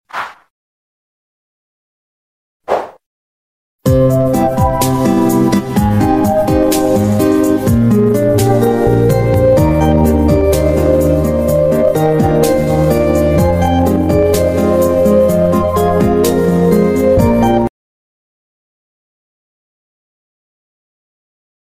Sony PlayStation Screen Of Death Sound Effects Free Download